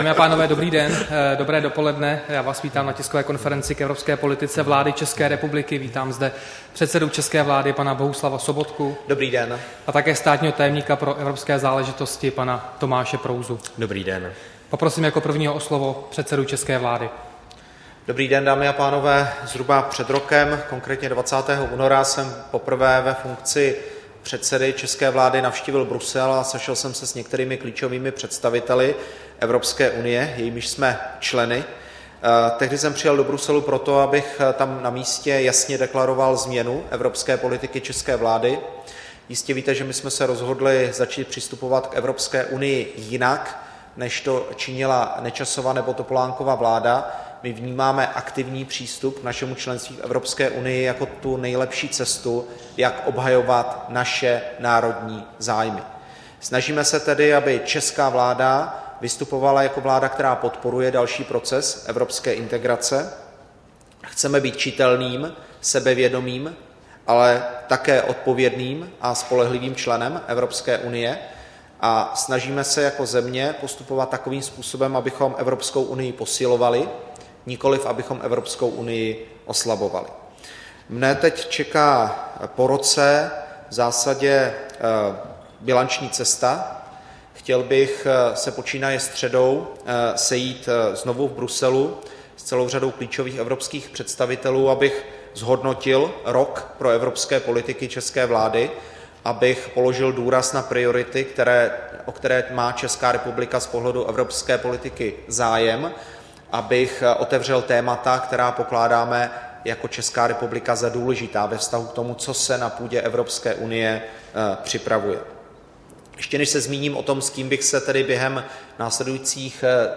Tisková konference premiéra Bohuslava Sobotky a státního tajemníka pro evropské záležitosti Tomáše Prouzy, 17. března 2015